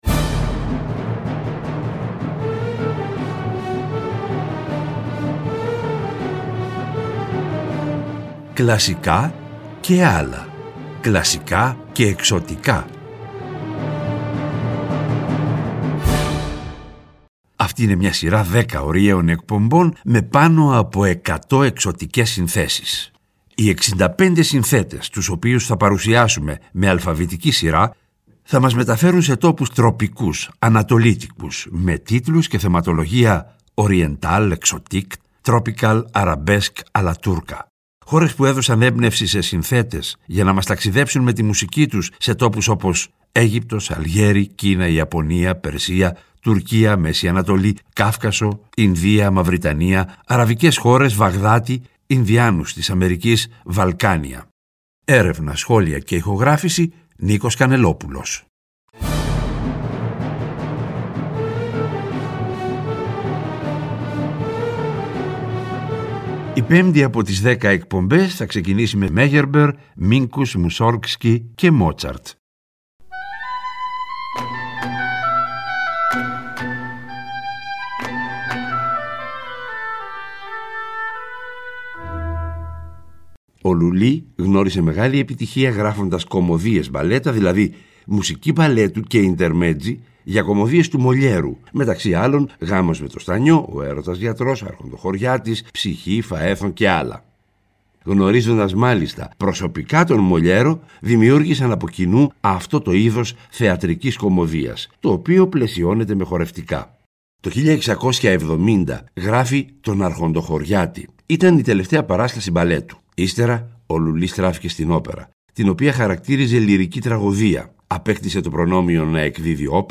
Τον Απρίλιο τα «Κλασικά και ..Άλλα» παίρνουν χρώμα Ανατολής και γίνονται «Κλασικά και …Εξωτικά», σε μια σειρά 10 ωριαίων εκπομπών, με πάνω από 100 εξωτικές συνθέσεις.